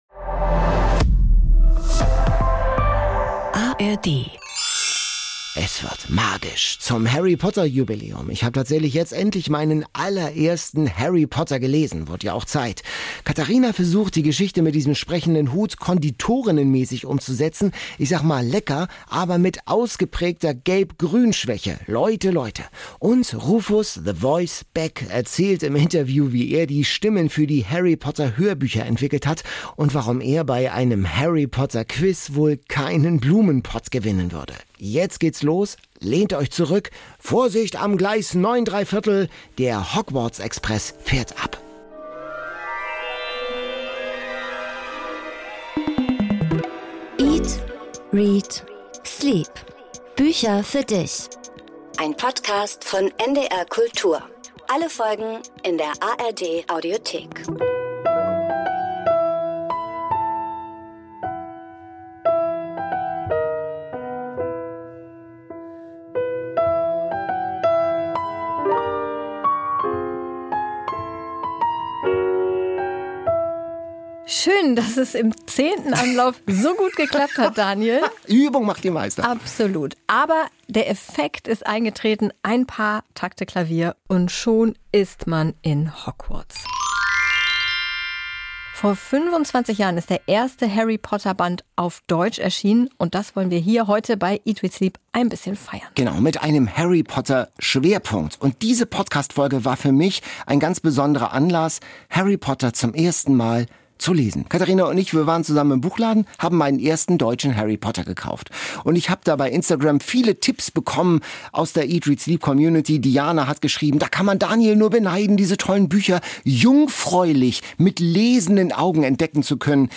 Das wird hier mithilfe der literarischen Vorspeise ermittelt! Eine magische Folge zum deutschen Harry Potter Jubiläum mit Stimmen aus der Community, Anekdoten von Rufus „the voice“ Beck und Fun Facts rund um den berühmtesten Zauberer der Welt.